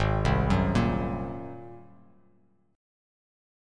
Ab9 Chord
Listen to Ab9 strummed